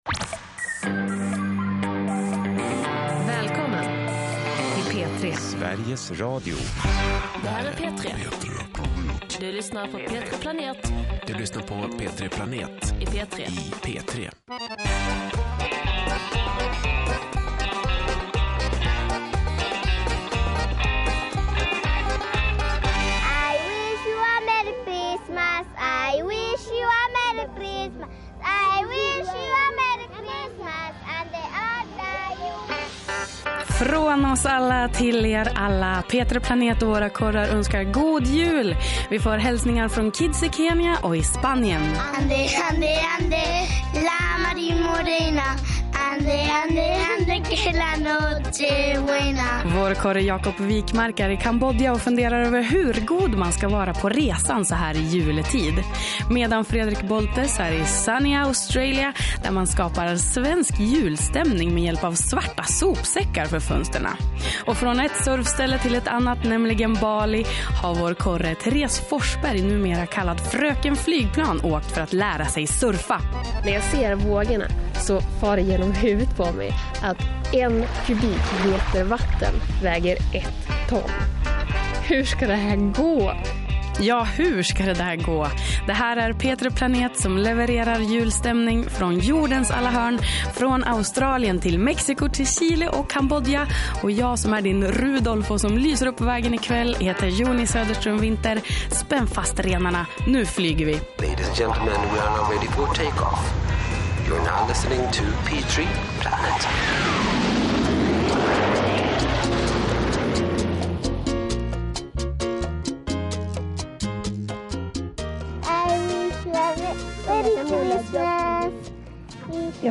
Here you can download and listen to her report. if you start listening 40 min and 30 seconds into the program you find the part about surfcamp lombok.